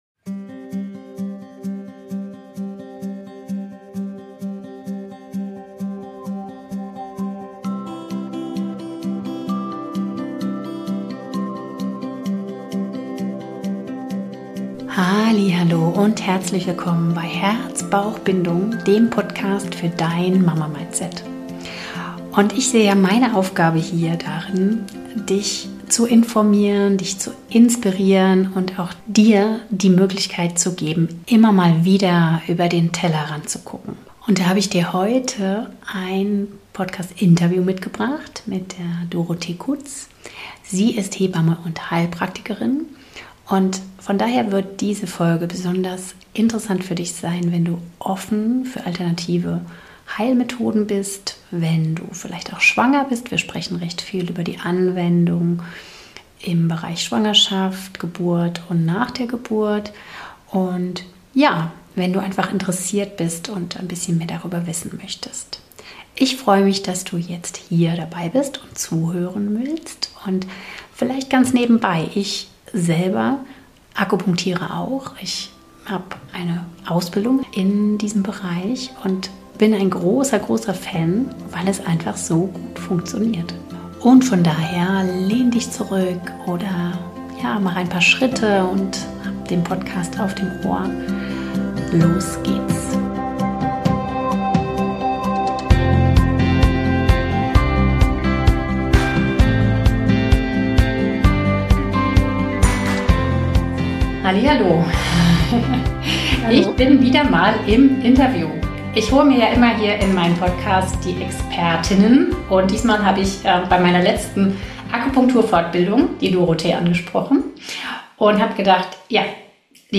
#120 Akupunktur und co. - Interview